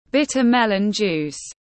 Nước ép mướp đắng tiếng anh gọi là bitter melon juice, phiên âm tiếng anh đọc là /’bitə ‘melən ˌdʒuːs/